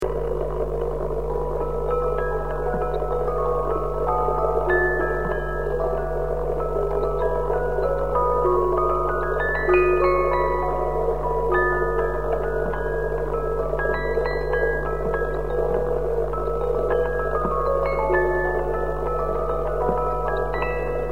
Old Music Box EDELWEISS Records
Needless to say that the quality of these recordings is poor, if measured with nowadays (CD) standards.